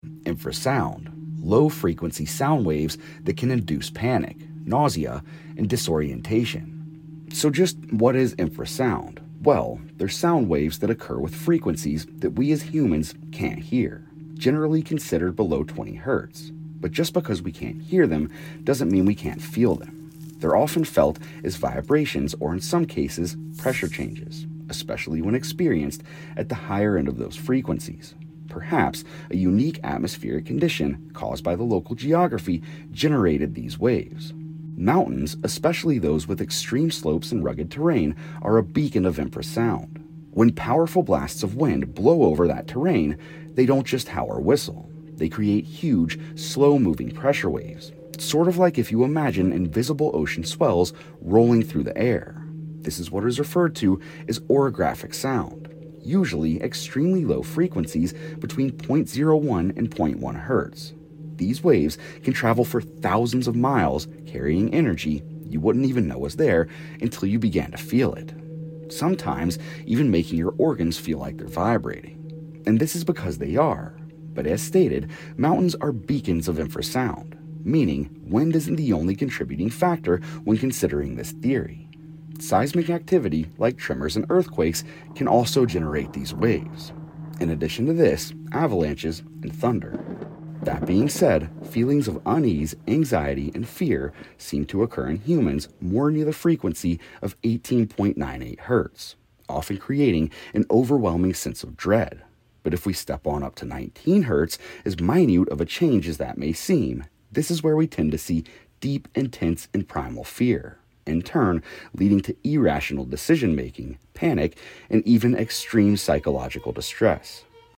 Infrasound sound effects free download